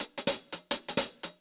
Index of /musicradar/ultimate-hihat-samples/170bpm
UHH_AcoustiHatB_170-02.wav